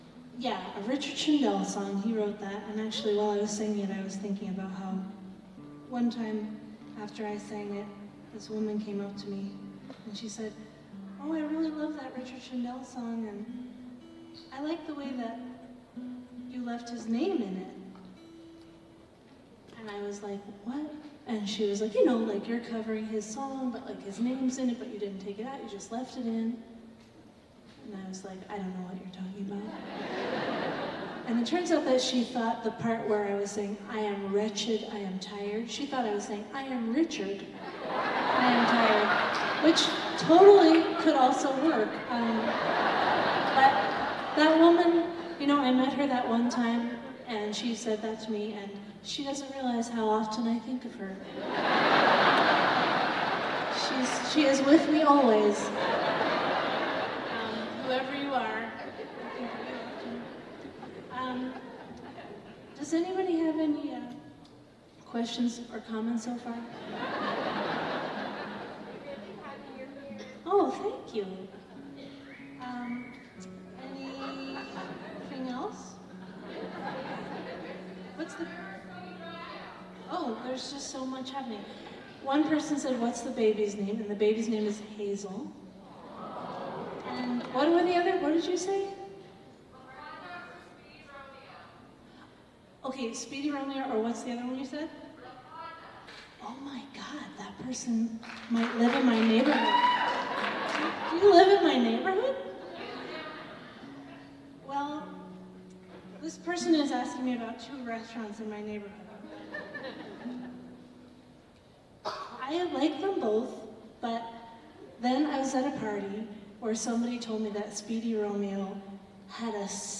lifeblood: bootlegs: 2024: 2024-05-07: barbara b. mann performing arts hall - fort myers, florida